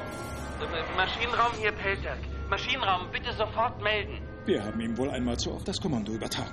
Datei Dateiversionen Dateiverwendung Dialog_5_SG1_5x01.mp3 (Dateigröße: 50 KB, MIME-Typ: audio/mp3 ) Beschreibung Beschreibung Dialogzitat als Audiodatei Quelle SG1_05x01 SG1 5x01 Todfeinde Urheber bzw.